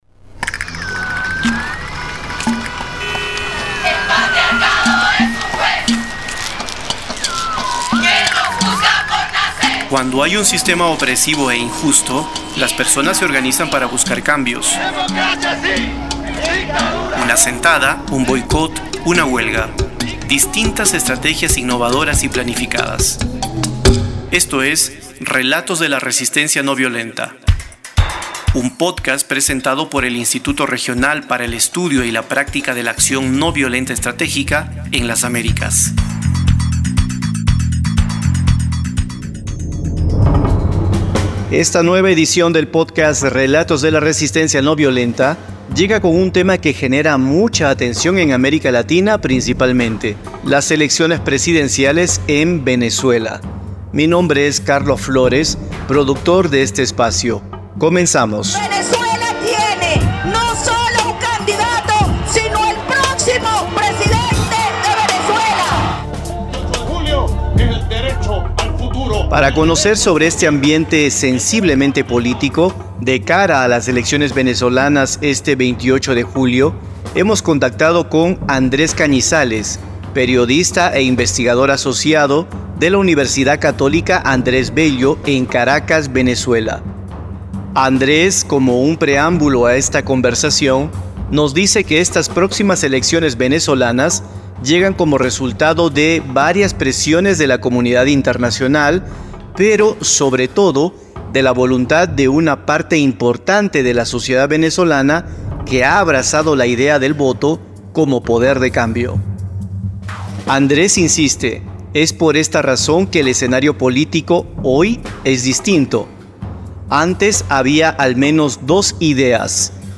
Tamaño: 47.56Mb Formato: Basic Audio Descripción: Entrevista - Acción ...